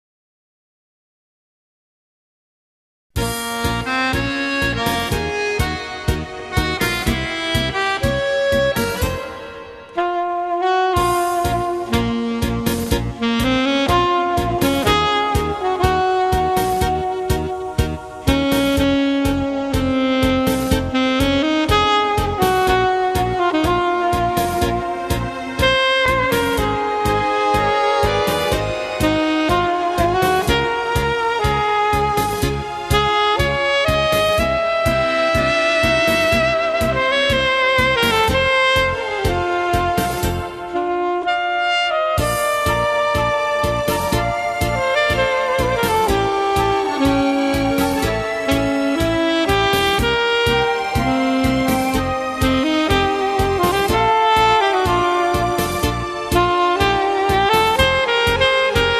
Tango
12 brani per sax e orchestra.